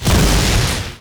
weapon_lightning_008_cut.wav